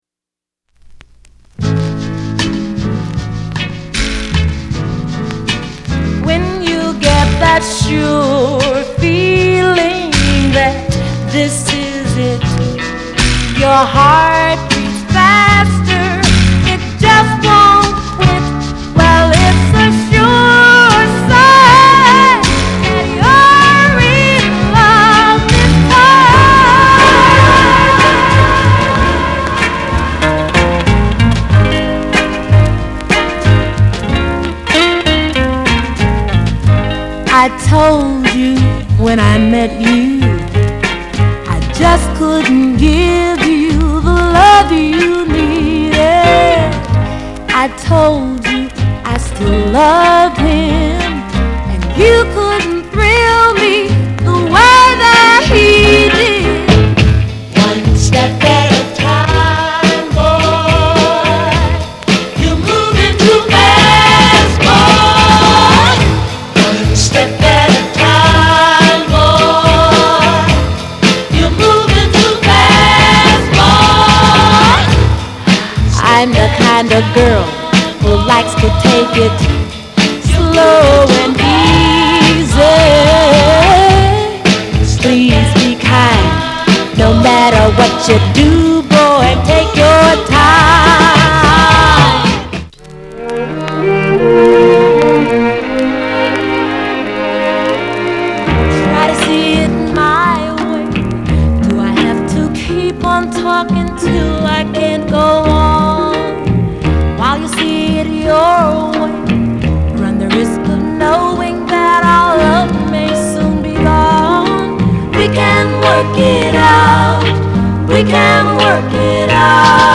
R&B、ソウル